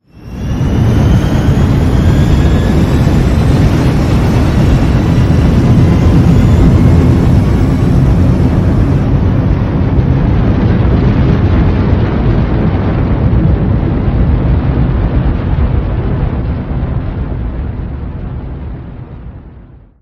ships / Movement / launch9.wav
launch9.wav